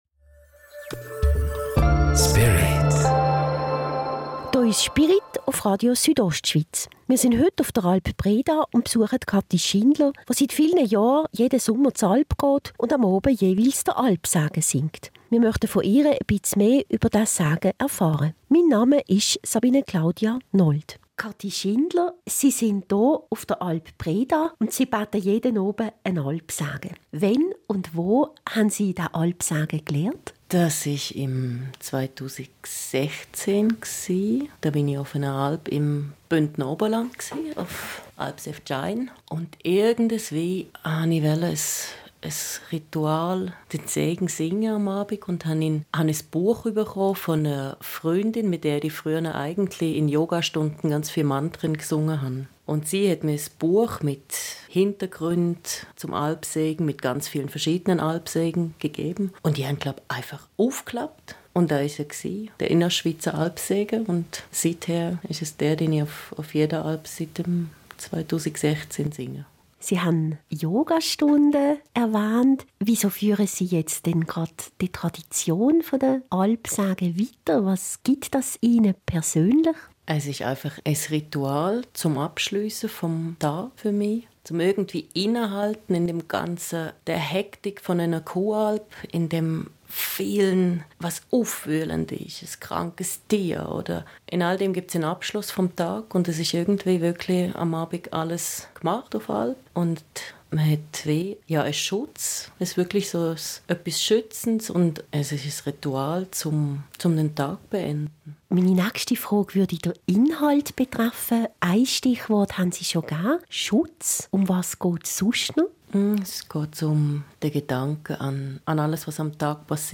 Spirit Alp-Segen 7.8..MP3